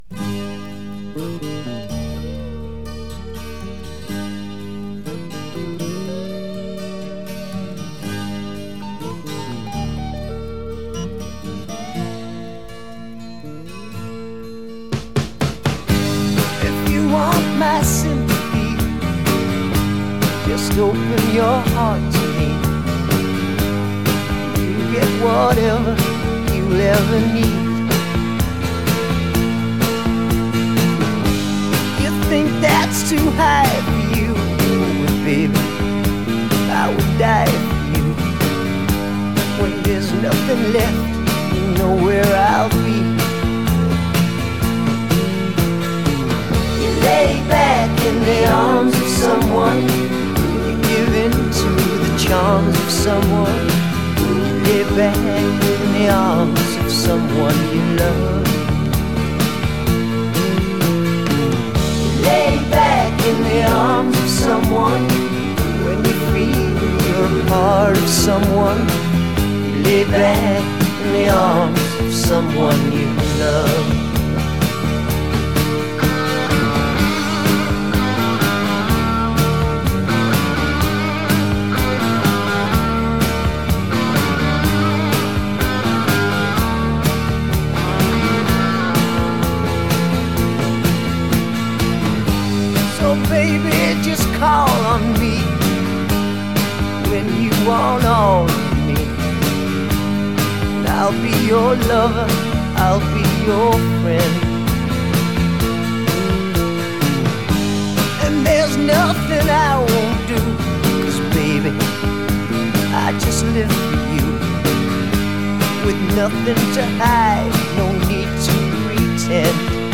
Genre: Pop Rock.